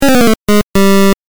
レトロゲーム （105件）
8bit失敗3.mp3